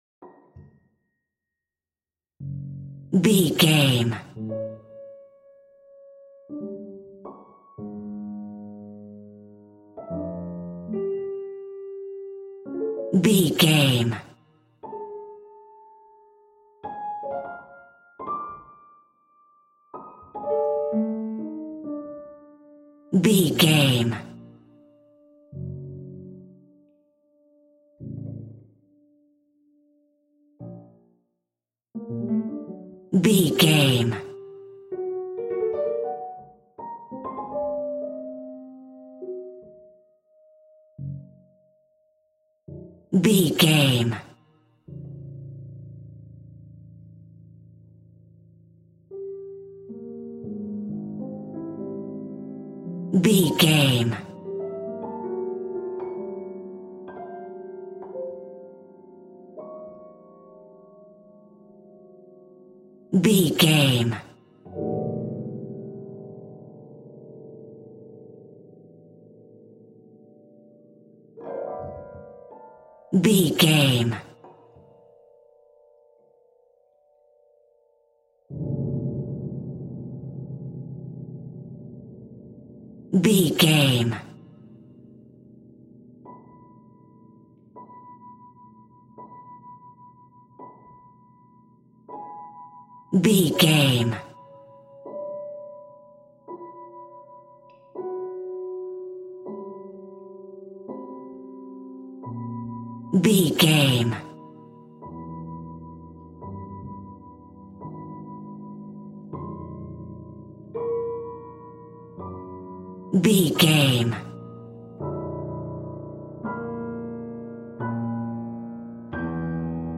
In-crescendo
Aeolian/Minor
tension
ominous
eerie
horror music
Horror Pads
horror piano
Horror Synths